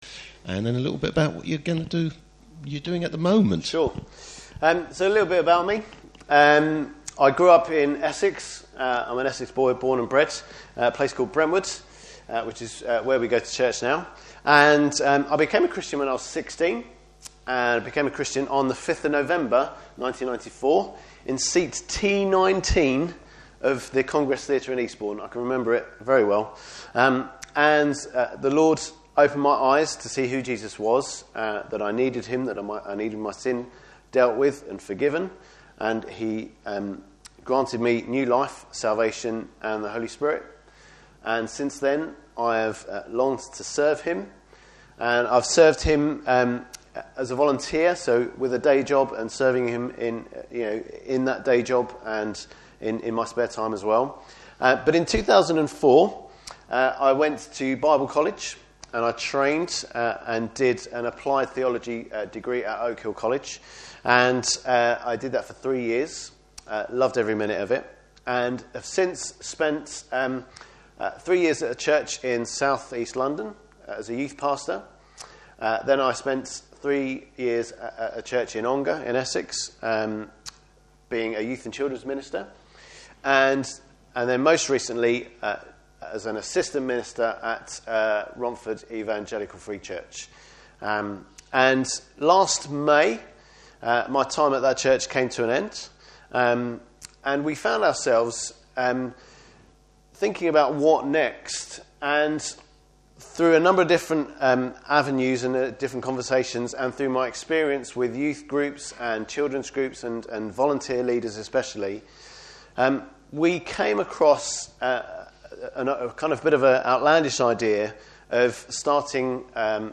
Service Type: Morning Service Preacher